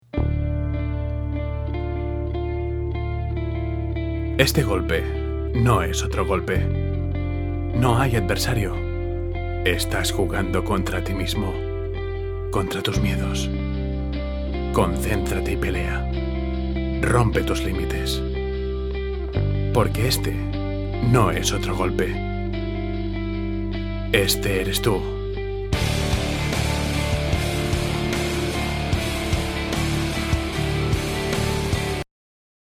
Voz masculina adulto joven. Timbre grabe, dulce y profundo. Posee una musicalidad sensual y atractiva, cálida, próxima, segura y natural
Sprechprobe: Sonstiges (Muttersprache):